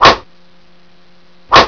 Whip Whoosh 3 Sound Effect Free Download
Whip Whoosh 3